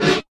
Campfire Snare.wav